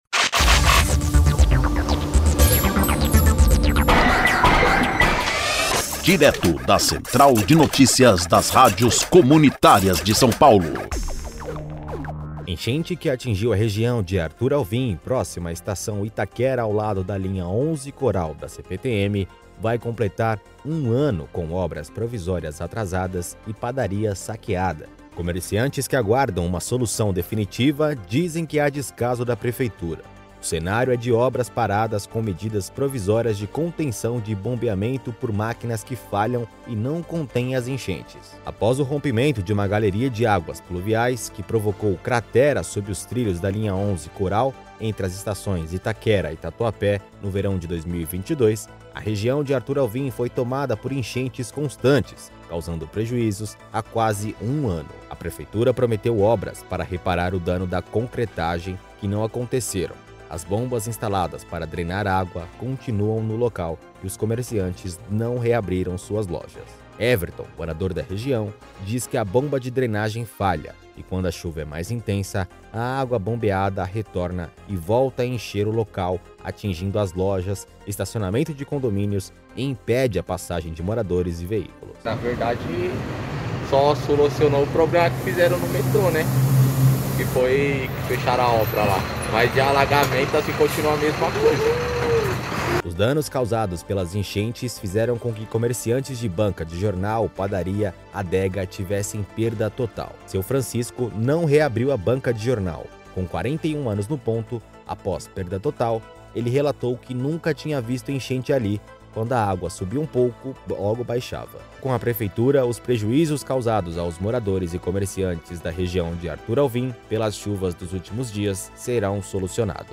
INFORMATIVO: Promessa da prefeitura para obras pluviais completará um ano
5-NOTICIA-Promessa-da-prefeitura-para-obras-pluviais-completara-umano-LIBERTACAO.mp3